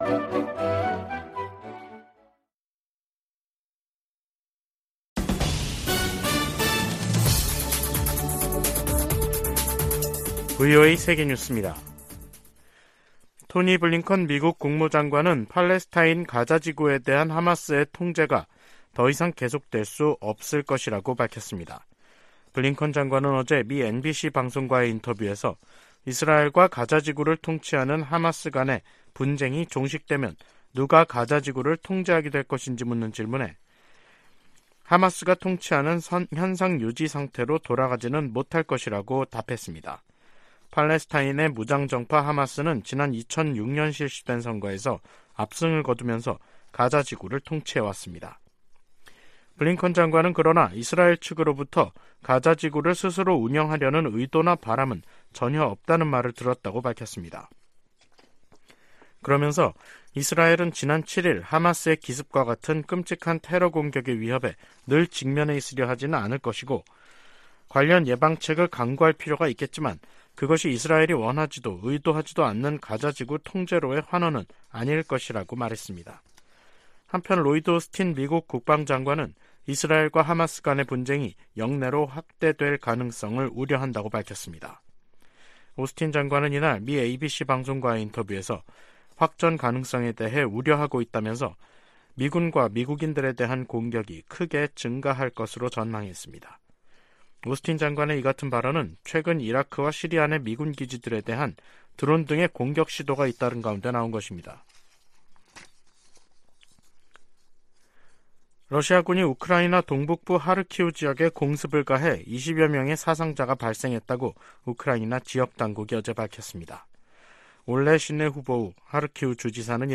VOA 한국어 간판 뉴스 프로그램 '뉴스 투데이', 2023년 10월 23일 2부 방송입니다. 미국 백악관은 북한에서 군사 장비를 조달하려는 러시아의 시도를 계속 식별하고 폭로할 것이라고 강조했습니다. 미국 정부가 북한과 러시아의 무기 거래 현장으로 지목한 라진항에 또다시 컨테이너 더미가 자리했습니다.